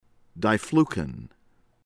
DIEZ, HORMANDO VACA ohr-MAHN-doh   VAH-kah  DEE-ehss